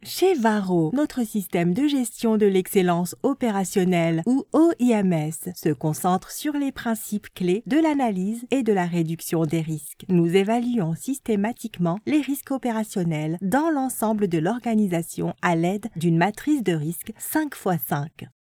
Best Female Voice Over Actors In January 2026
Award winning Chinese Voice Artist who is Conversational, believable, friendly, real.